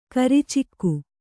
♪ karicikku